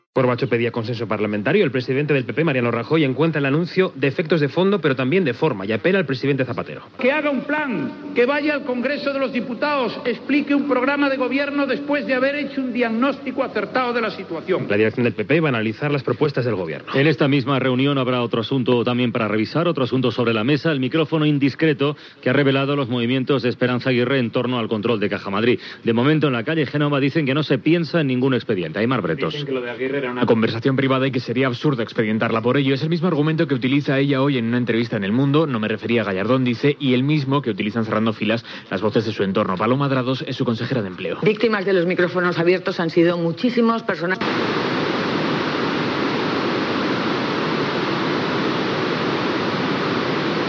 Últims segons d'emissió de Ràdio Terrassa, en connexió amb la cadena SER, abans que deixés d'emetre per sempre.
Declaracions de Mariano Rajoy del Partido Popular, paraules d'Esperanza Aguirre sobre Caja Madrid
Informatiu